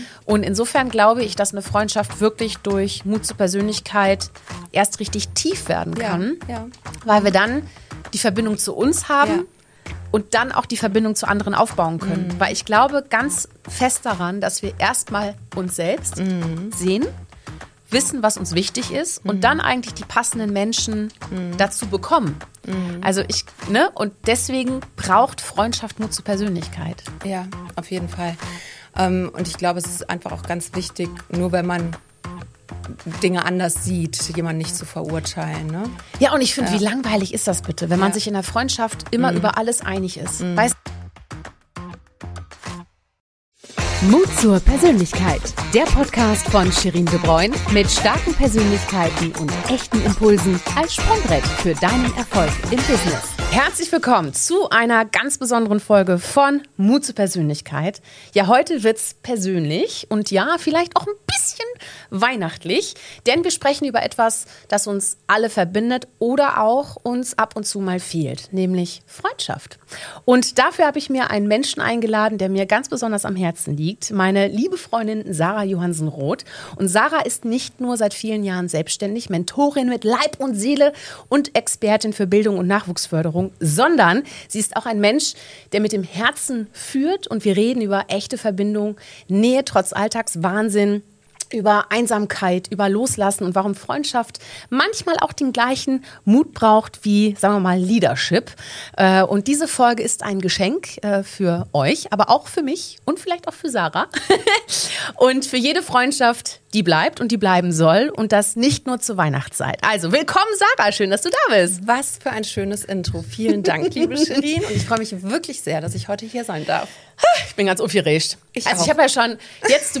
Und ein besinnliches Ständchen gibt's am Ende auch noch dazu...